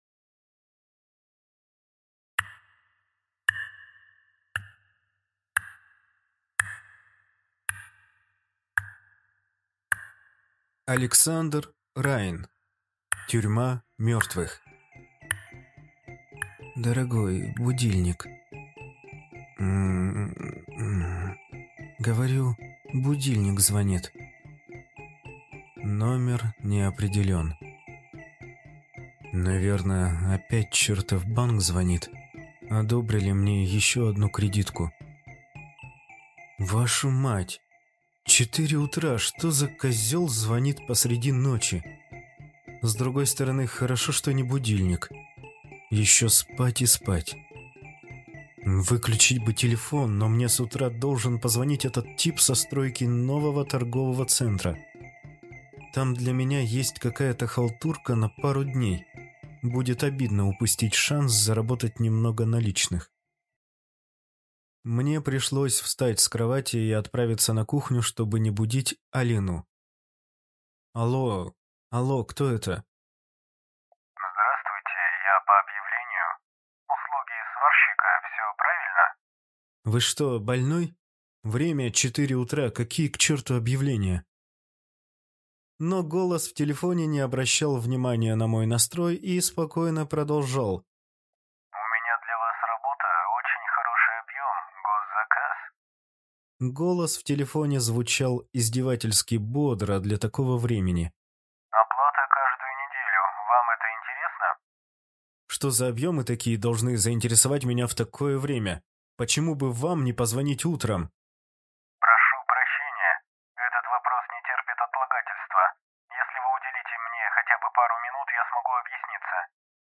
Аудиокнига Тюрьма мертвых | Библиотека аудиокниг